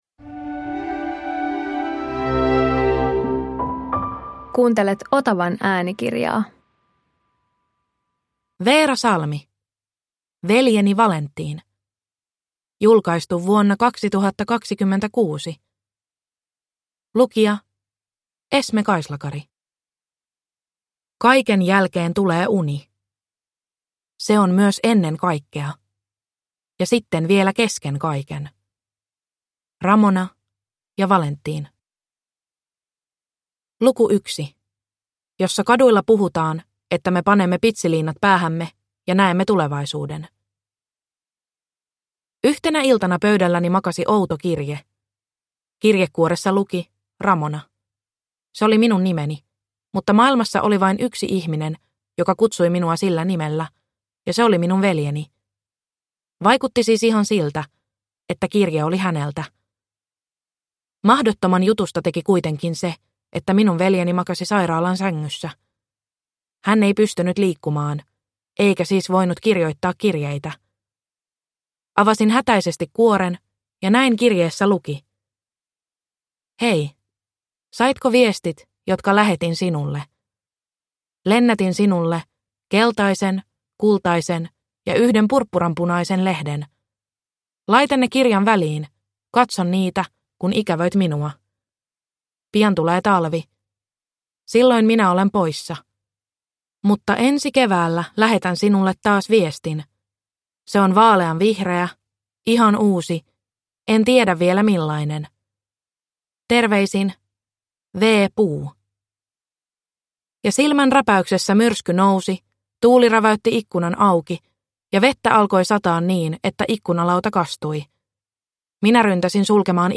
Veljeni Valentin – Ljudbok